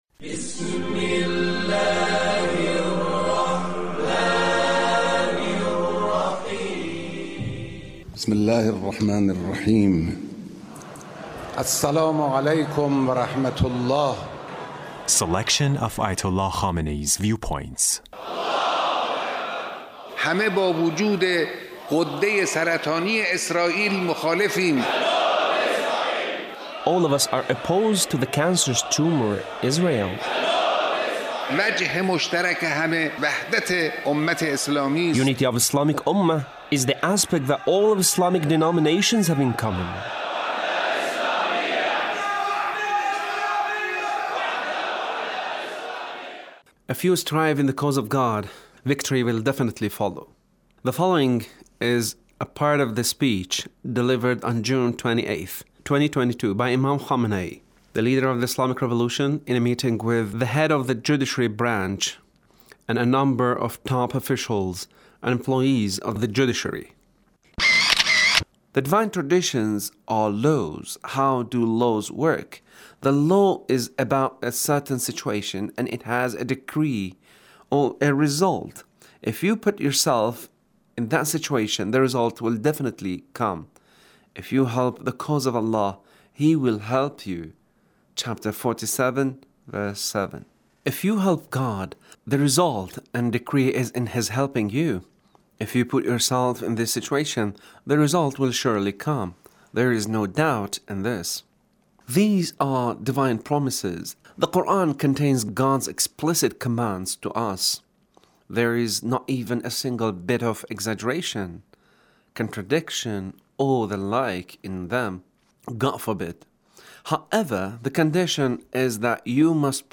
Leader's Speech with Judiciary Officials